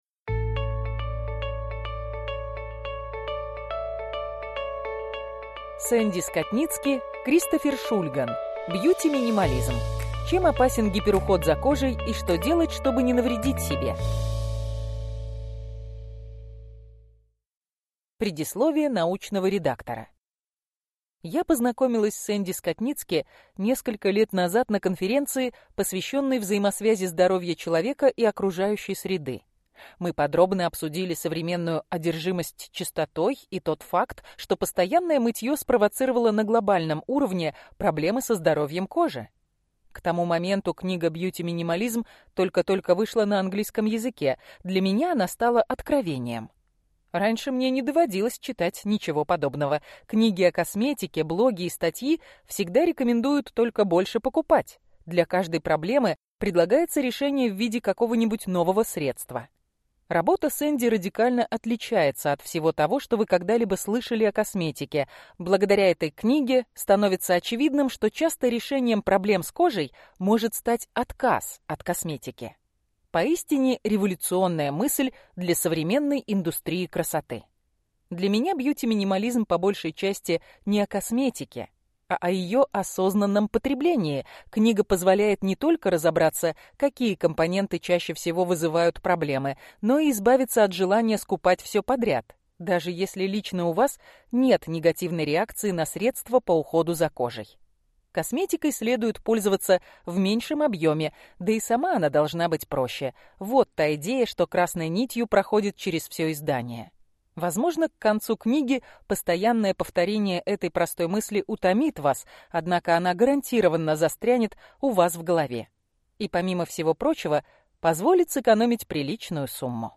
Аудиокнига Бьюти-минимализм. Чем опасен гиперуход за кожей и что делать, чтобы не навредить себе | Библиотека аудиокниг